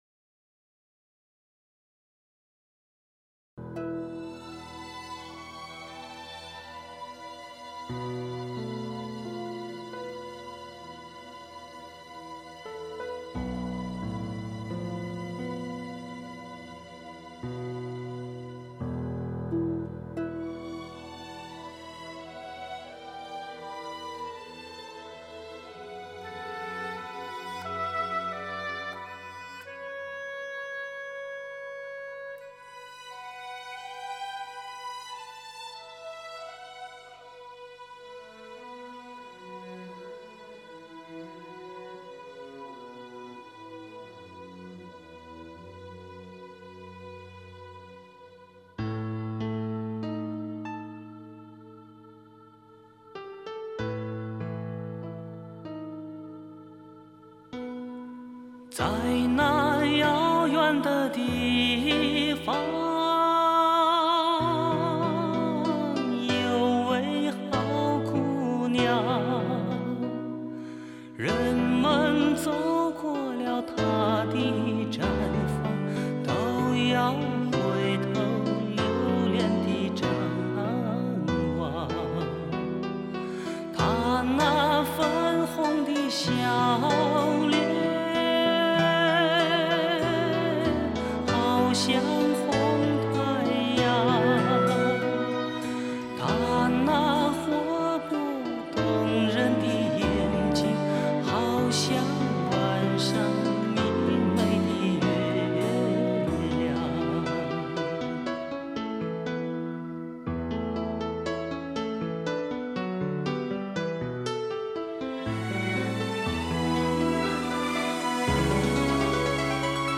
试听的是低品质WMA